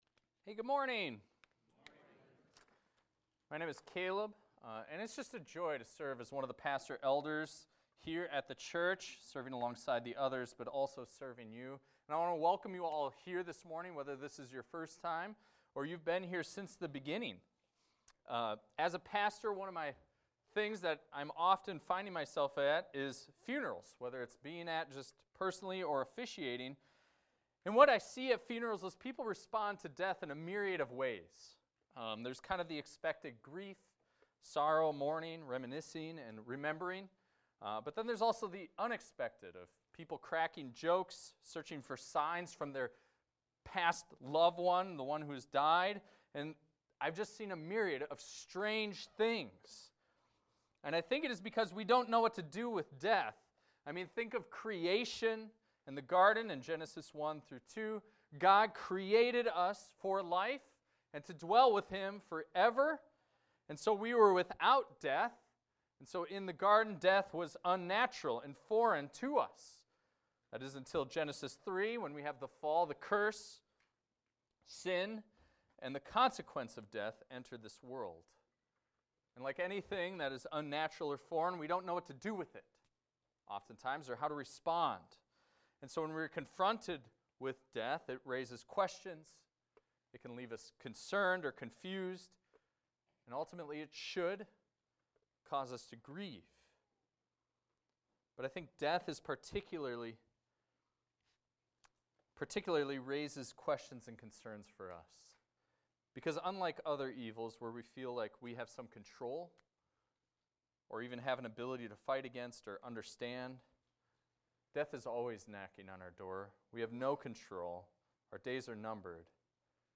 This is a recording of a sermon titled, "When the Trumpet Sounds."